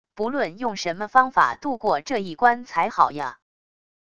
不论用什么方法渡过这一关才好呀wav音频生成系统WAV Audio Player